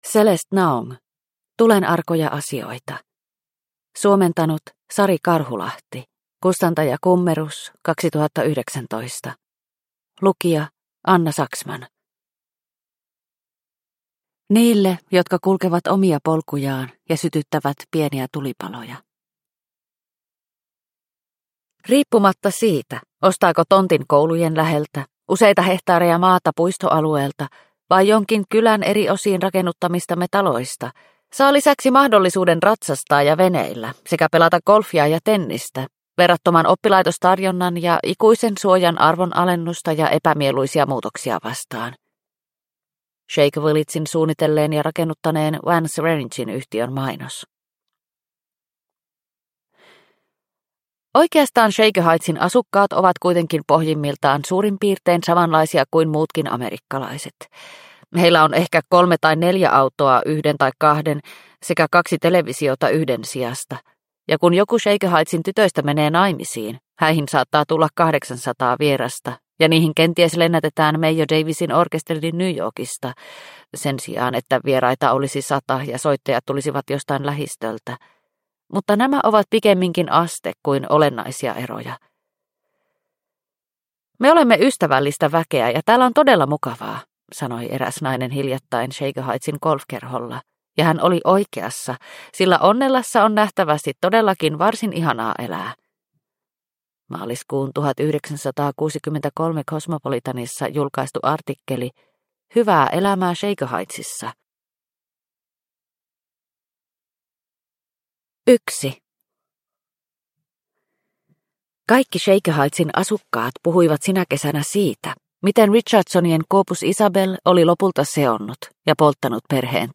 Tulenarkoja asioita – Ljudbok – Laddas ner